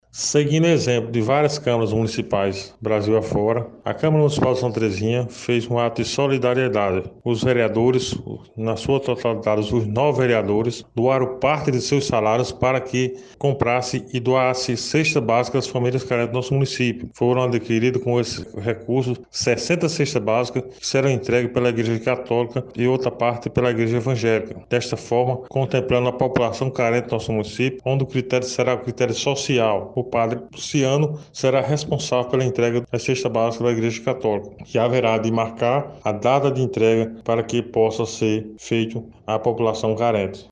Escute o vereador: